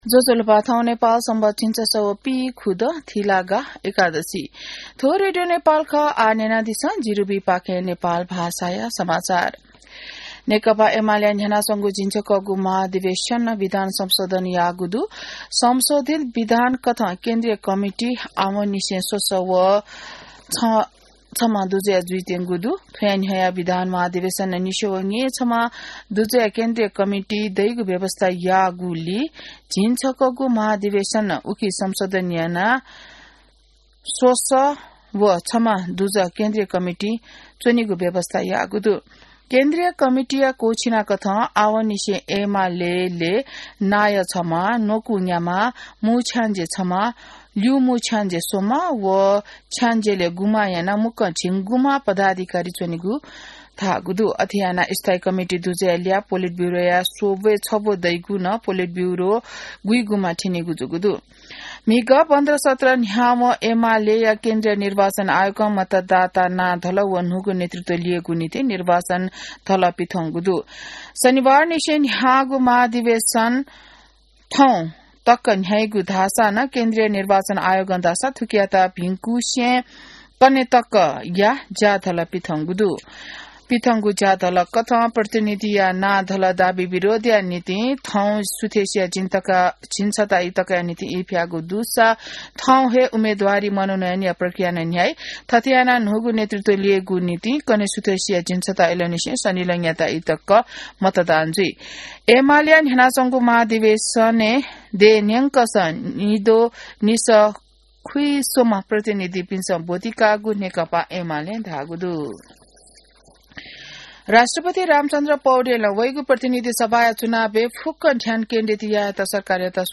An online outlet of Nepal's national radio broadcaster
नेपाल भाषामा समाचार : २९ मंसिर , २०८२